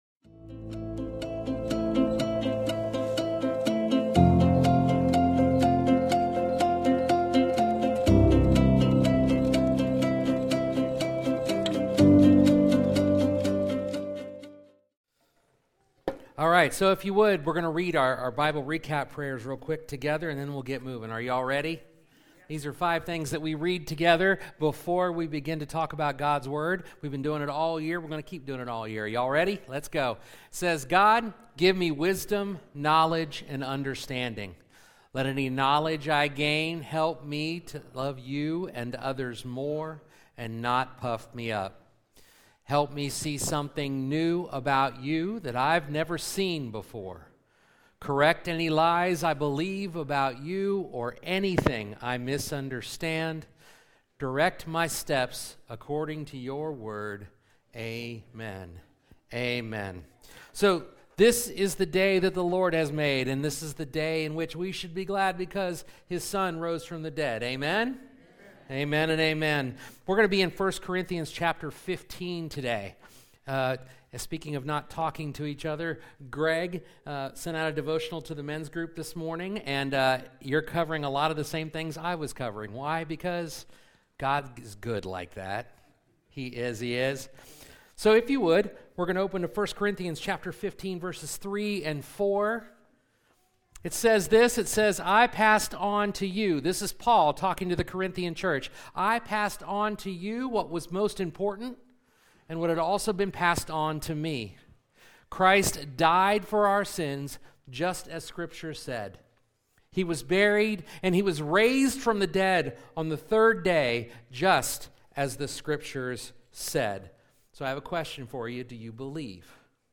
Sermons | Gretna Brethren Church